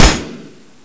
river.wav